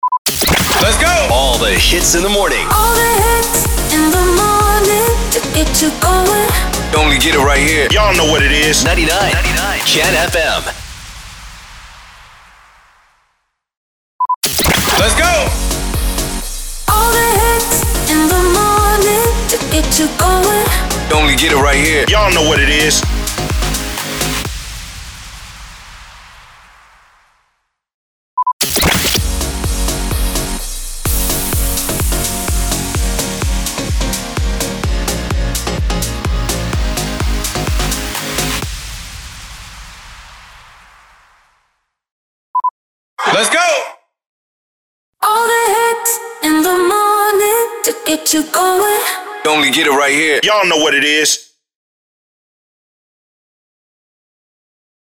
621 – SWEEPER – MORNING SHOW
621-SWEEPER-MORNING-SHOW.mp3